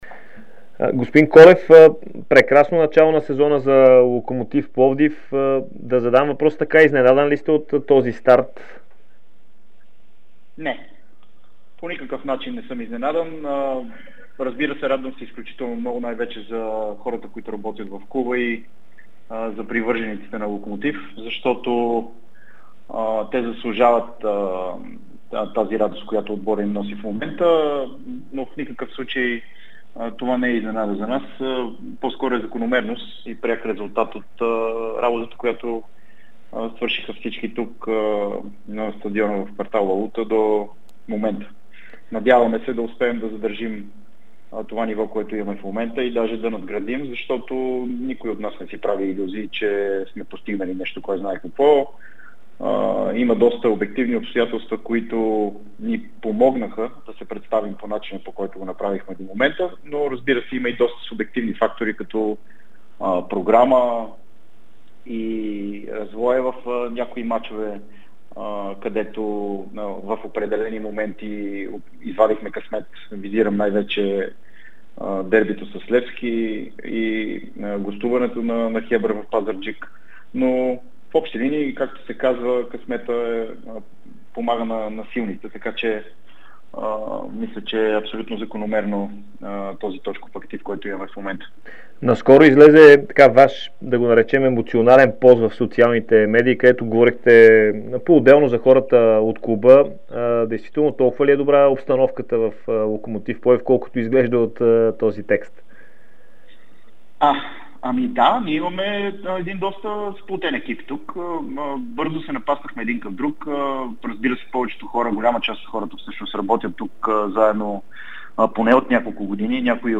ексклузивно интервю пред Дарик радио и dsport